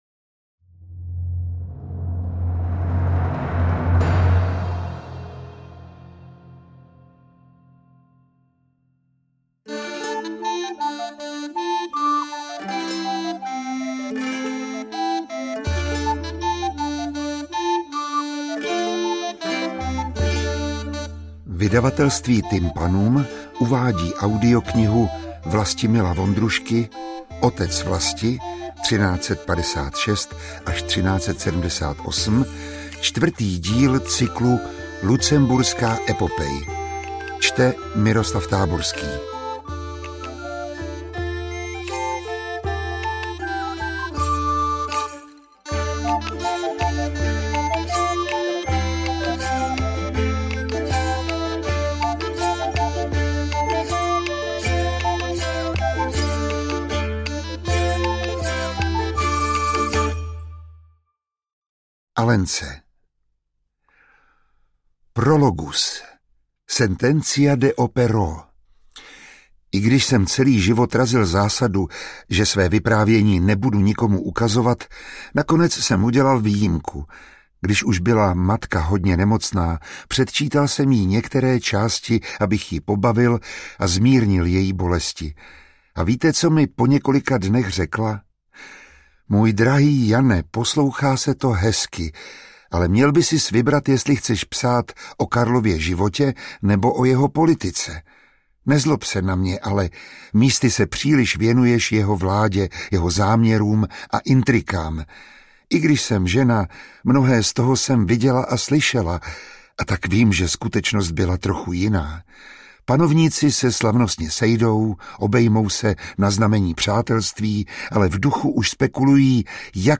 Interpret:  Miroslav Táborský